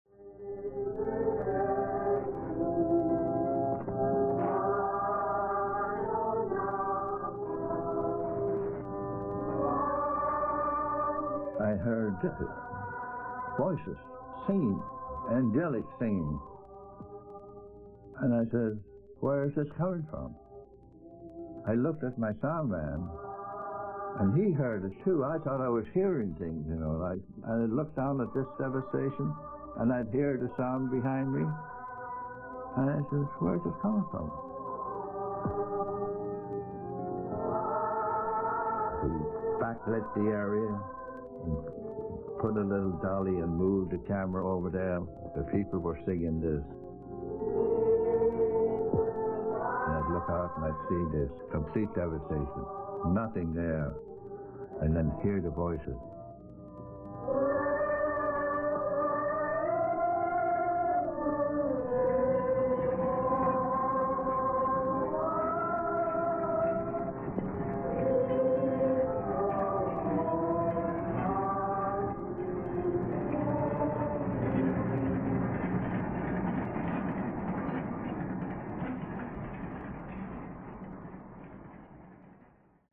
Christmas 1945, at Urakami Cathedral, Nagasaki.